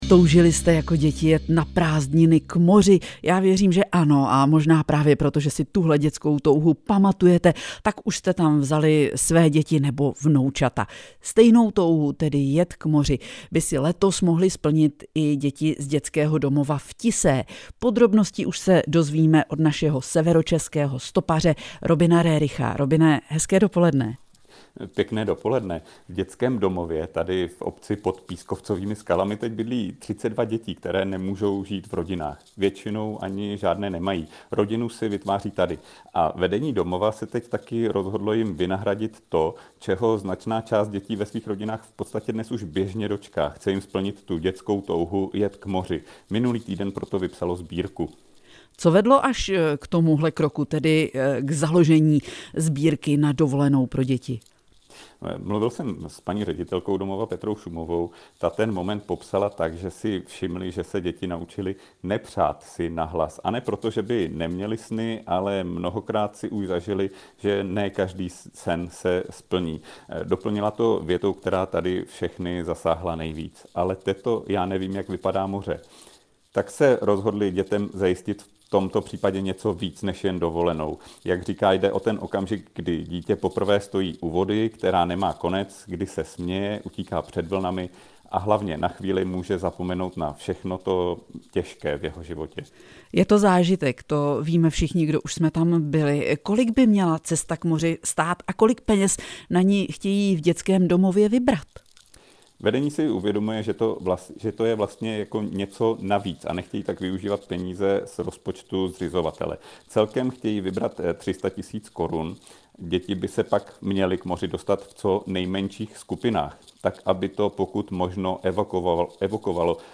reportaz-cr-sever-ke-sbirce